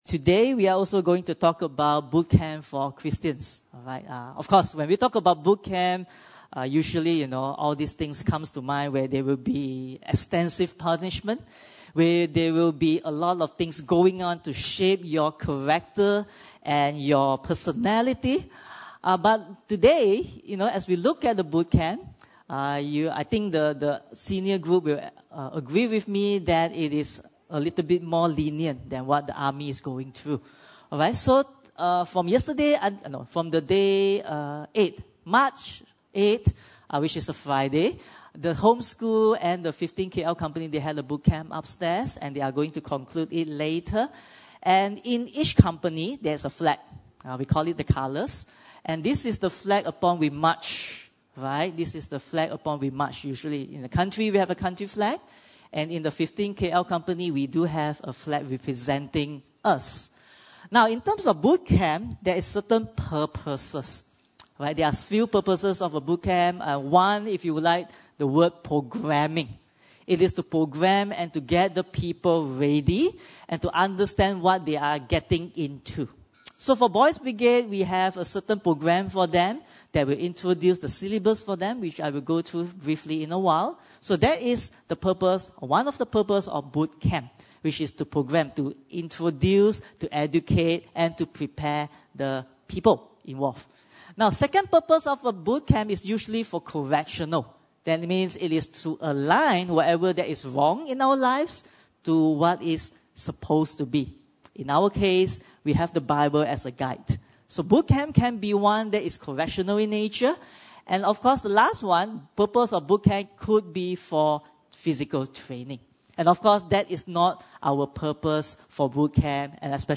2 Corinthians 2:14-17 Service Type: Sunday Service